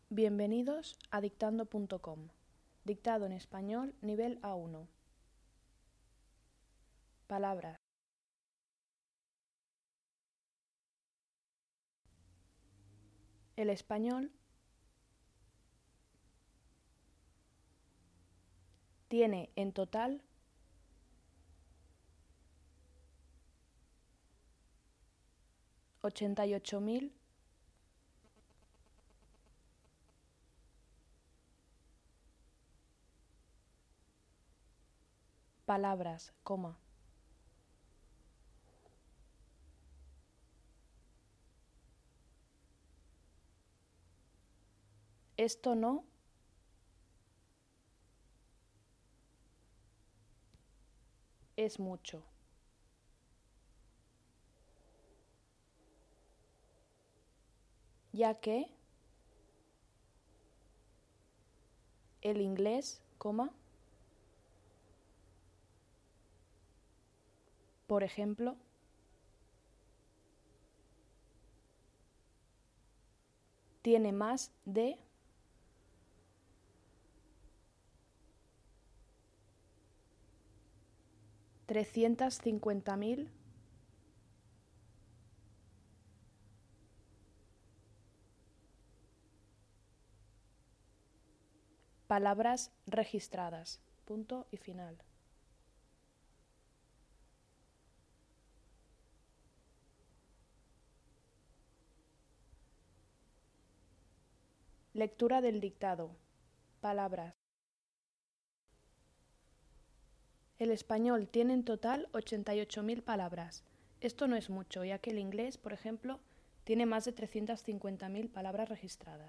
Audio del ejercicio 2: Dictado
Audio-1-Dictado.mp3